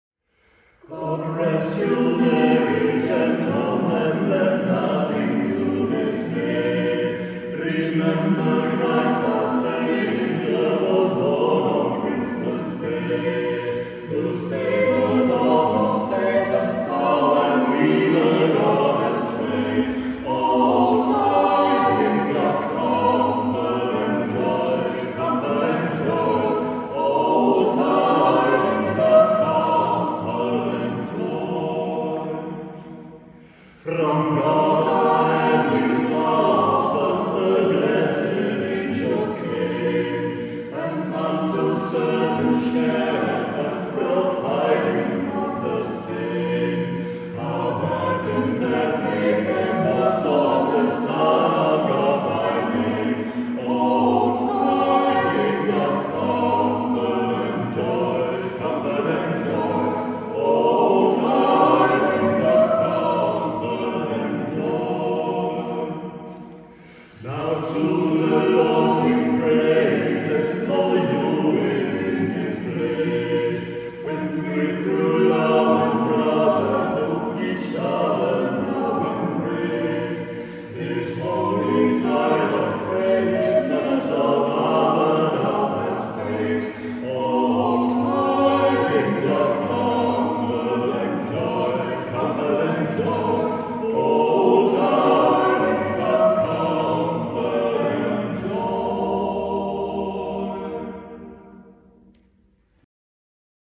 Het moet in 1985 geweest zijn dat ik bij wijze van oefening een arrangement gemaakt heb van het Kerstlied "God rest you merry gentlemen” voor vierstemmig koor.
audio opname van een repetitie van het Arianna-kwartet die dit arrangement hebben uitgevoerd in hun kerstconcert van 2025 (zie video).